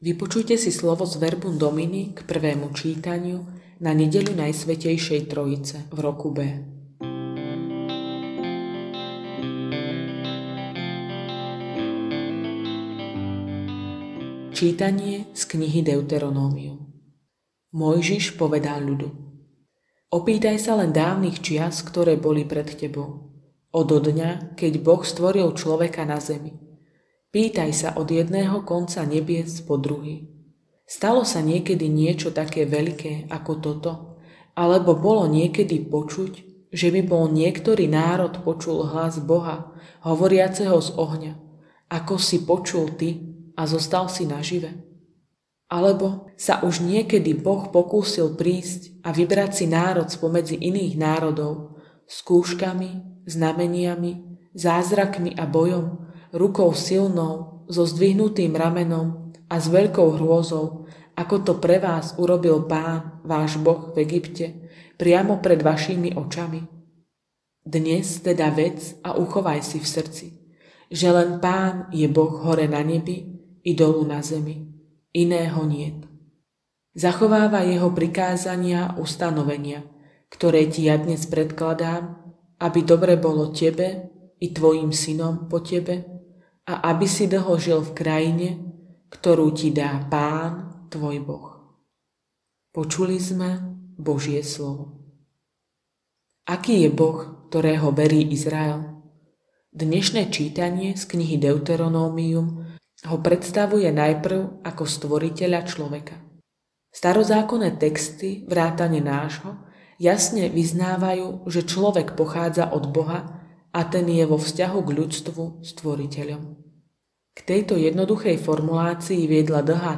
Audionahrávka zamyslenia…